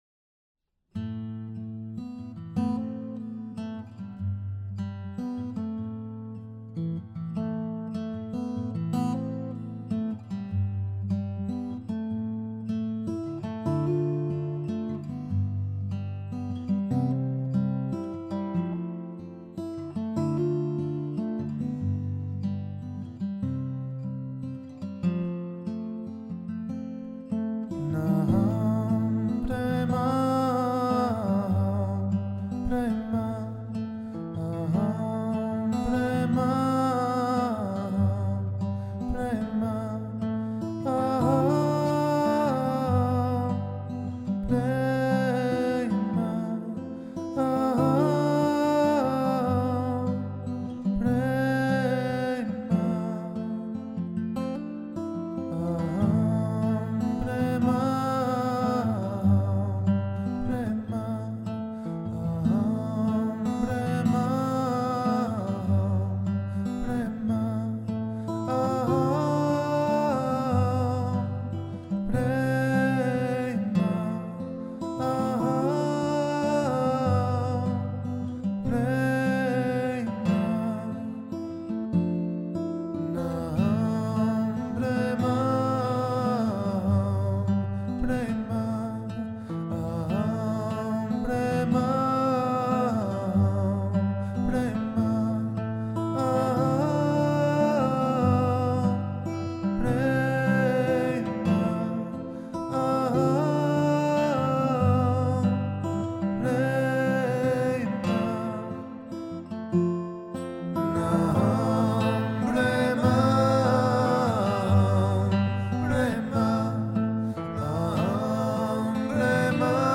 Ter ere van deze mijlpaal delen we iets heel speciaals: de mantra AHAM PREMA, prachtig ingezongen door Eloi Youssef, voormalig zanger van Kensington en zelf een Enneagramtype 4. Deze krachtige mantra, die staat voor "Ik ben liefde" in het Sanskriet, nodigt je uit om de universele liefde in jezelf en anderen te herkennen en te omarmen.
Luister naar onze nieuwste afleveringen en laat je inspireren door inzichten, verhalen en de betoverende klanken van AHAM PREMA.